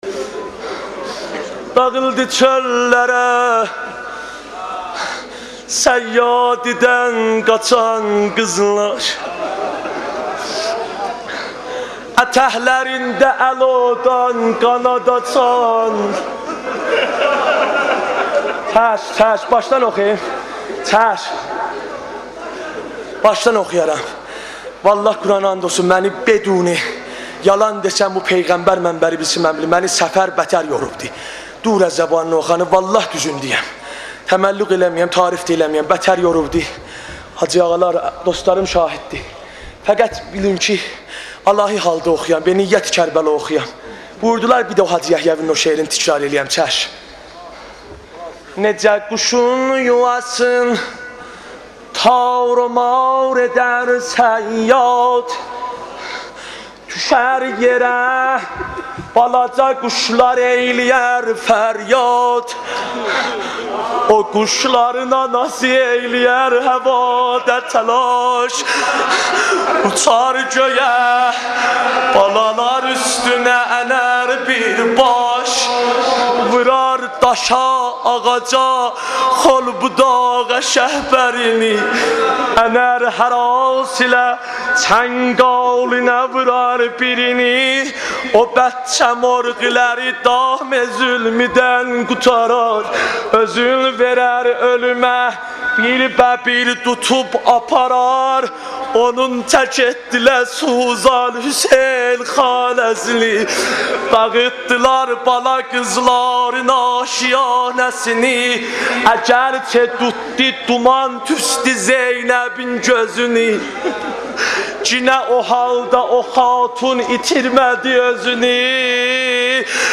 مداحی
روضه سوزناک در مدح اهل بیت امام حسین (ع) که اسیر هستند.